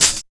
100 HAT 1 -R.wav